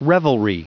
Prononciation du mot revelry en anglais (fichier audio)
Prononciation du mot : revelry